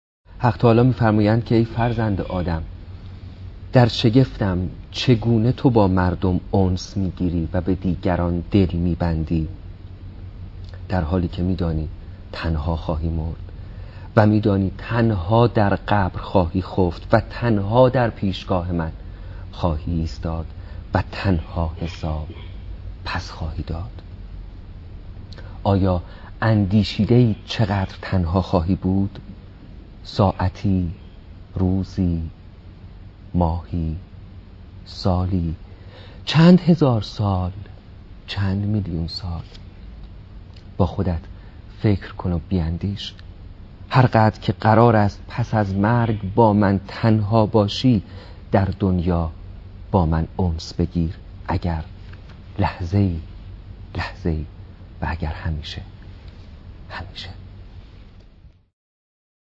نجم الدین شریعتی شرح حدیث قدسی
دانلود مستقیم فایل صوتی بیان حدیث قدسی ای فرزند ادم درشگفتم ،با صدای نجم الدین شریعتی.